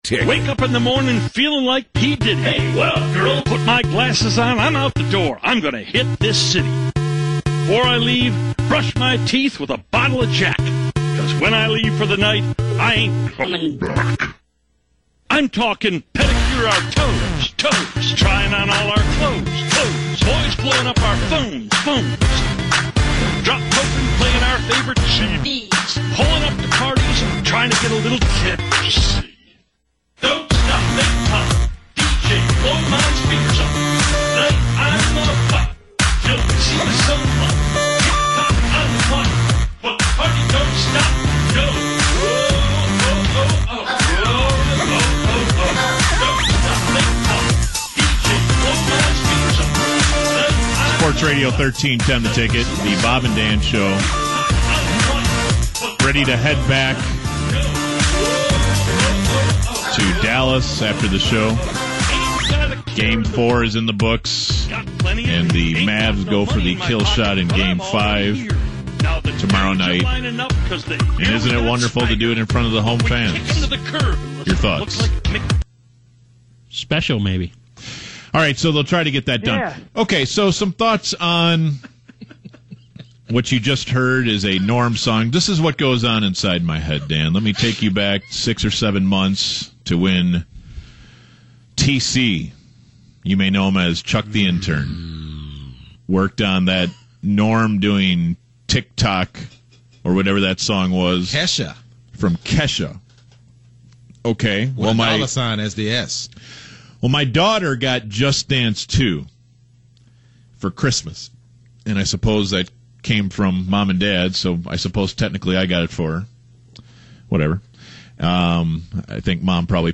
Totally cringe-inducing.